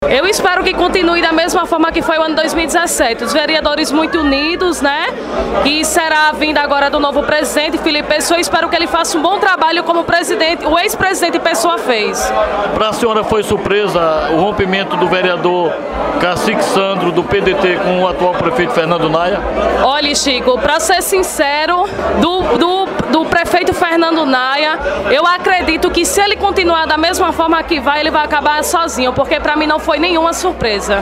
Ouça áudio da vereadora Amanda, sobre os bastidores da política local: